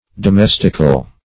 Search Result for " domestical" : The Collaborative International Dictionary of English v.0.48: Domestical \Do*mes"tic*al\, a. Domestic.